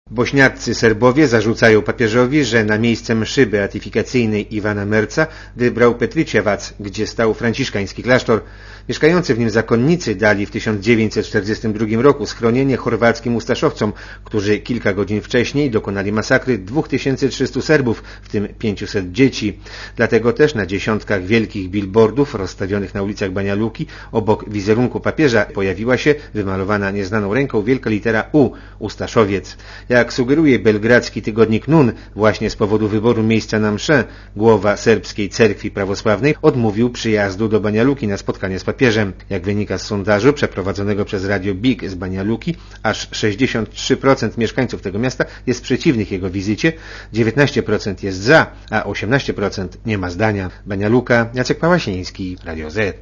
Posłuchaj relacji korespondenta Radia Zet (206 KB)